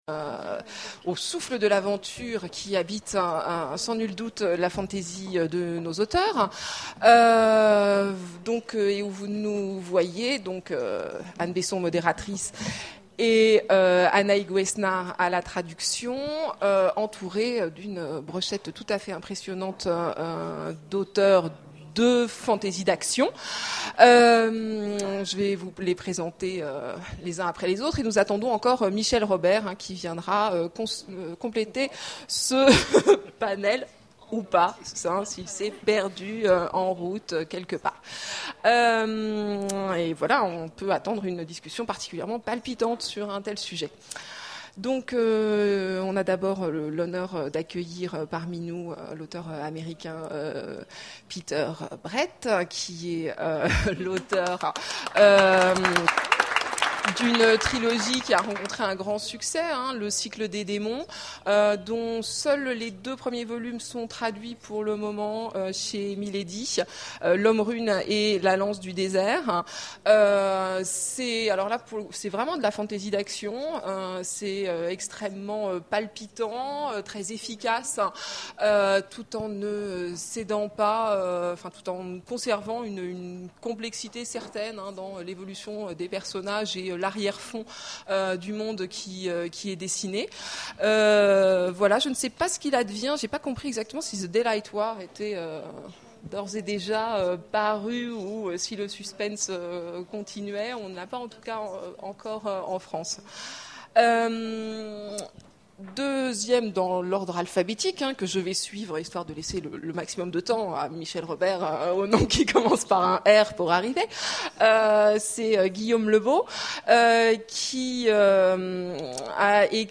Imaginales 2011 : Conférence La fantasy c'est avant tout le souffle de l'aventure...